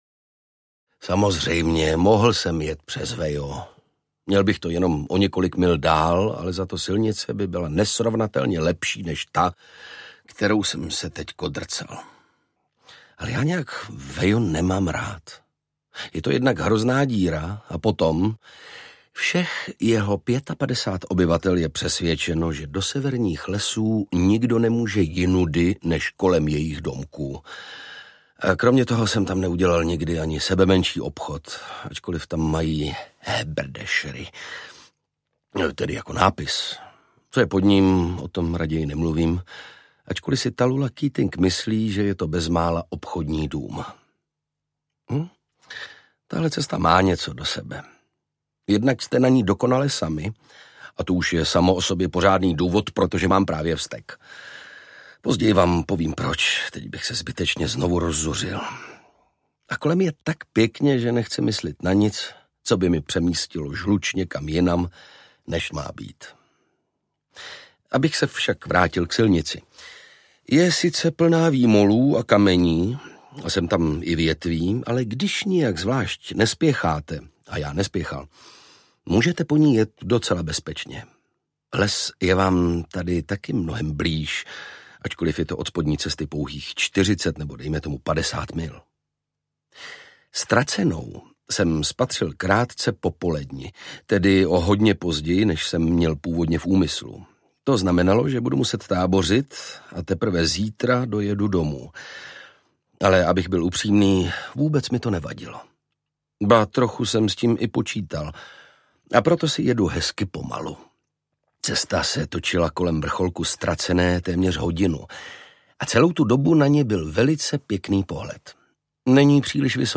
Čisté radosti mého života audiokniha
Ukázka z knihy
Čte Ondřej Brousek.
Vyrobilo studio Soundguru.